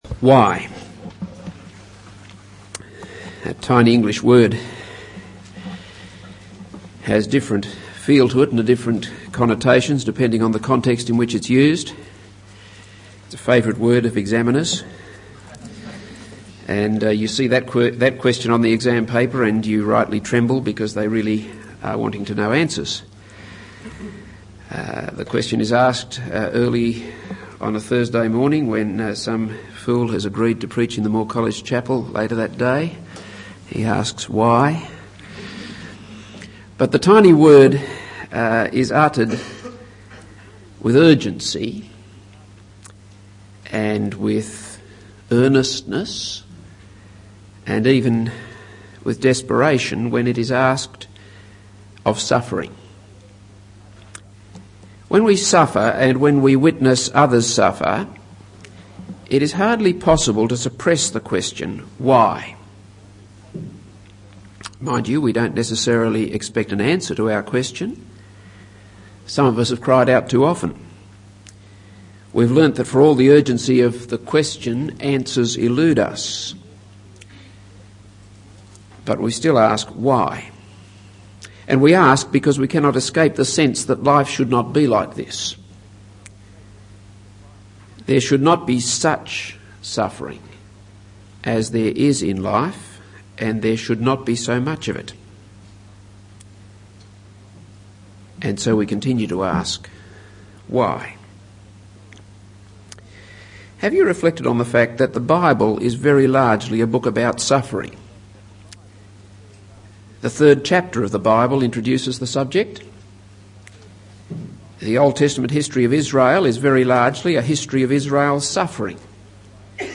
This is a sermon on 1 Samuel 21-23.